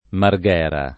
[ mar g$ ra ]